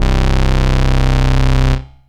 SYNTH BASS-1 0017.wav